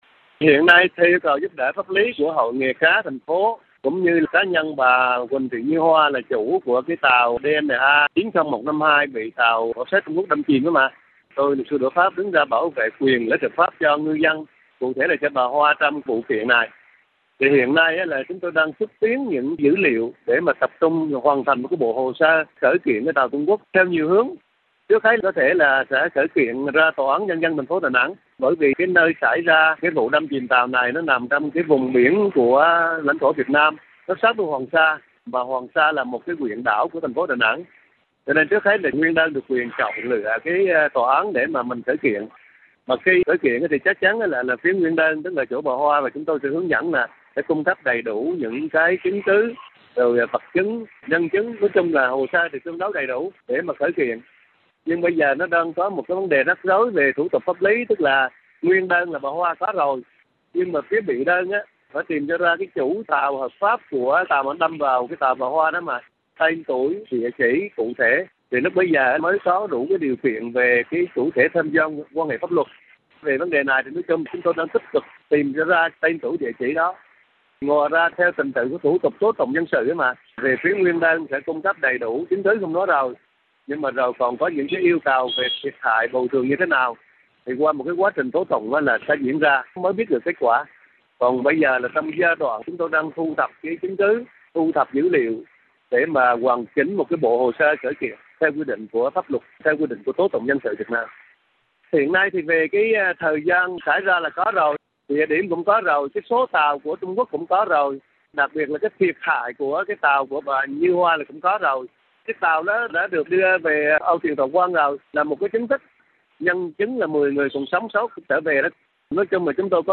Trả lời phỏng vấn của RFI Việt ngữ hôm nay